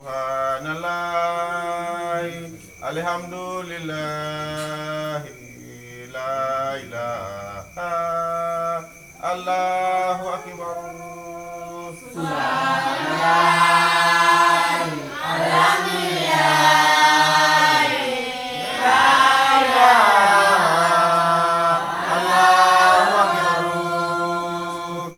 VOC 03.AIF.wav